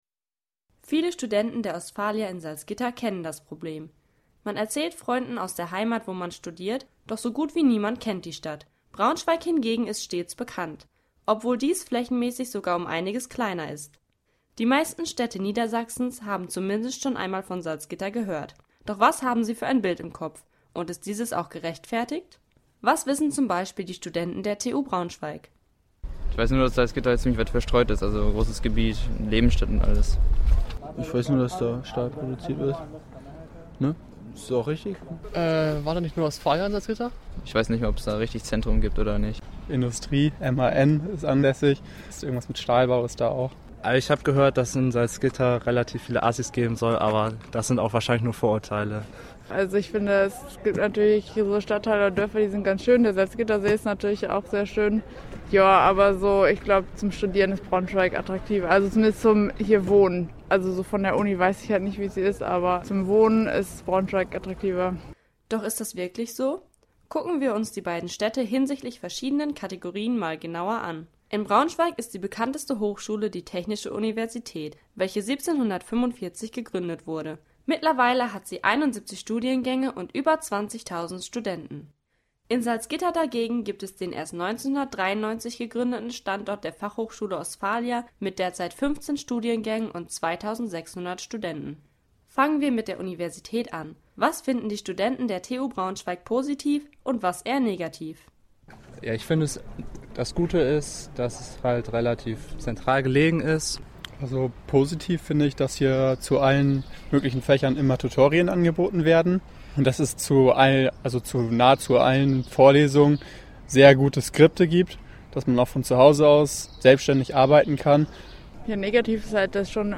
Campus38 vergleicht die beiden Hochschulstandorte auf Wohn-, Studier- und Freizeitmöglichkeiten und hört sich bei den Studis um.